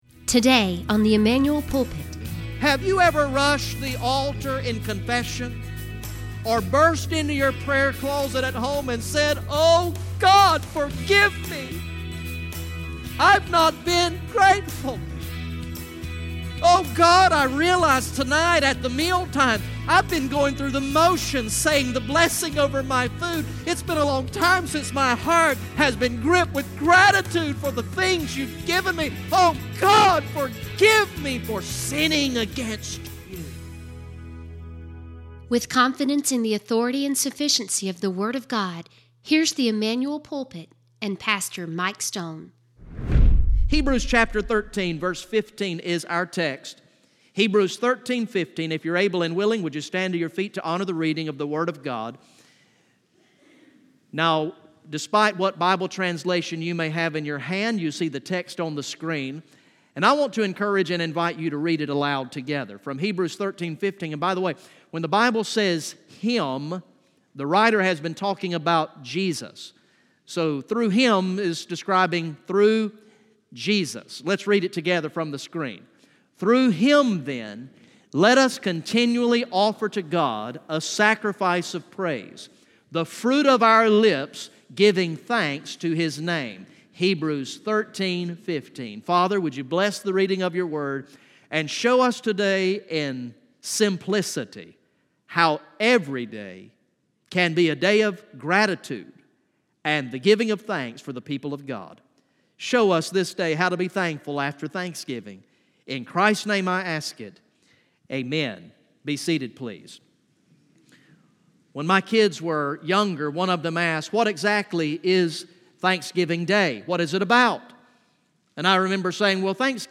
From the morning worship service on Sunday, November 25, 2018